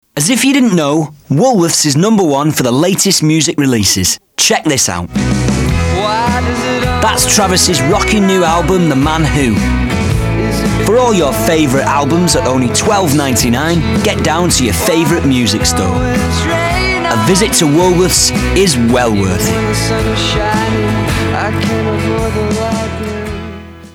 Woolsworth - Ad for a cd shop